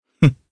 Kain-Vox_Happy1_jp.wav